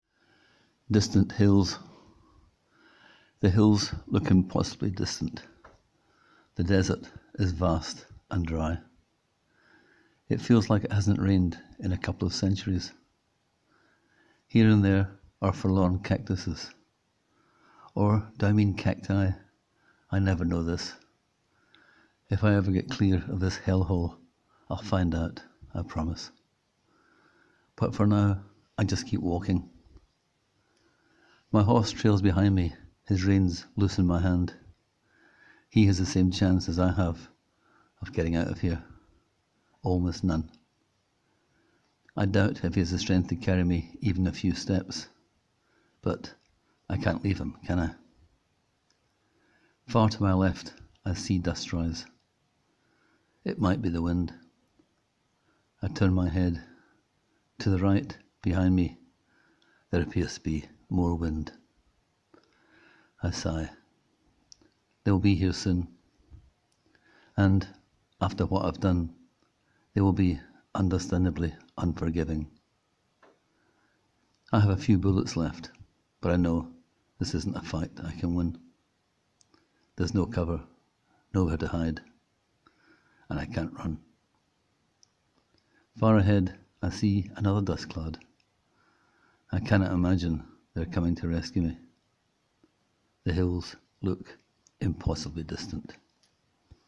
Click here to hear the author read the tale:
The words and the way you spoke created a hopeless, desolate picture in my mind.